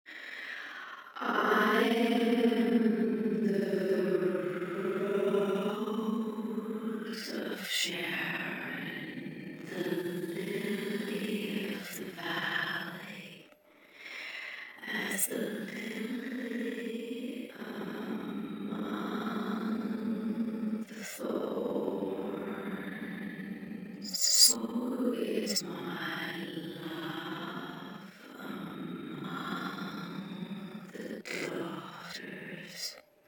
First of all, we have added a simple EQ to the sample, mainly to remove low frequencies, by lowering the low frequency gain and adjusting the cut-off frequency.
We also added a small EQ boost around 3 kHz with a medium narrow Q value.
We have also changed the grain parameters in this demo to a grain duration of 25 ms (to add some spectral broadening to the voice), and some random delays from a 50 ms range (to add some texture), and a modest 100 ms offset range for the same purpose, with grains sometimes being taken from neighbouring phonemes.
In this case, we tried to adjust it so the consonants wouldn’t be stretched as much (luckily they weren’t that strong in amplitude) and the stressed vowels would be given a greater stretch.